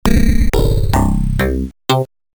Following a full 44 kHz 24 bits STEREO recording from original machines, this will be updated when new sounds (for suggestions drop me a edit LINE).
4 op synth edit According to the ST-01 likeness names, it is mostly probable that the sound sets comes from DX21 presets.
FunkBass Elec Bass
amiga-funkbass.mp3